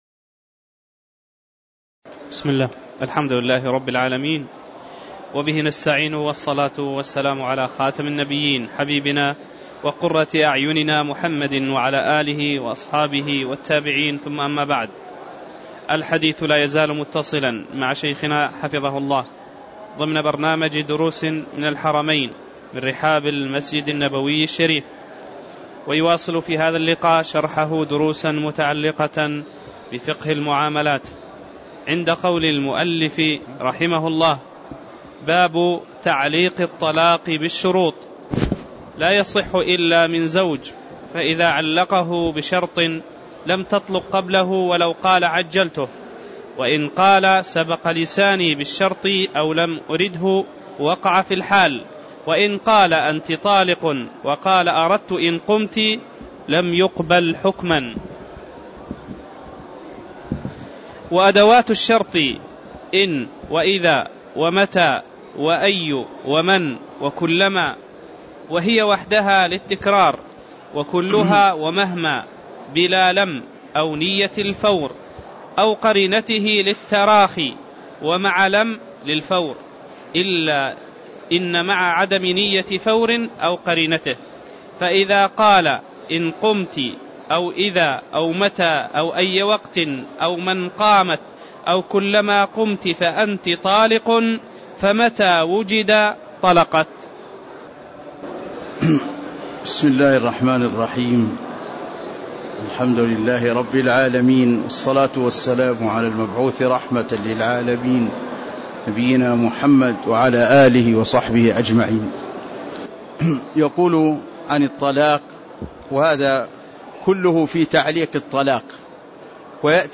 تاريخ النشر ٥ رجب ١٤٣٧ هـ المكان: المسجد النبوي الشيخ